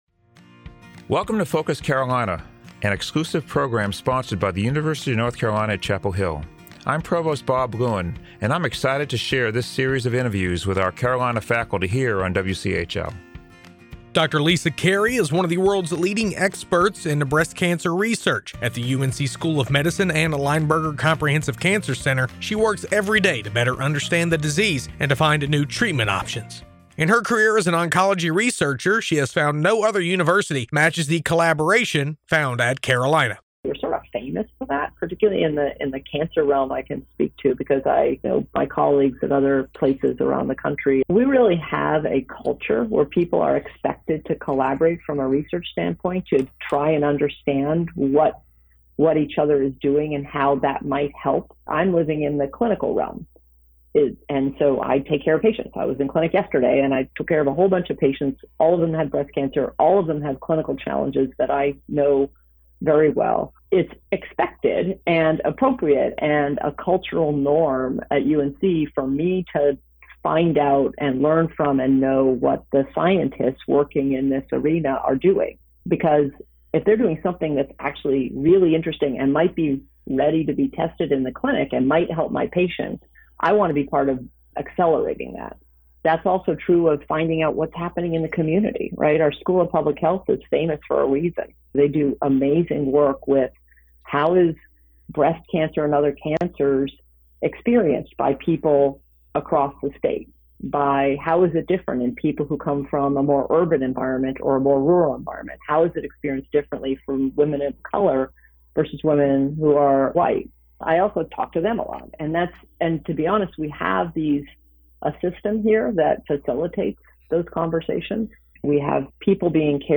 Tune in to Focus Carolina during morning, noon and evening drive times and on the weekends to hear stories from faculty members at UNC and find out what ignites their passion for their work. Focus Carolina is an exclusive program on 97.9 The Hill WCHL, sponsored by the University of North Carolina at Chapel Hill.